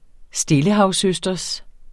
Udtale [ ˈsdeləhɑws- ]